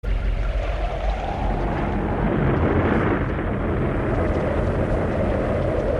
wind whistling sound effect.ogg
Original creative-commons licensed sounds for DJ's and music producers, recorded with high quality studio microphones.
[wind-whistling-sound-effect]__b0z.mp3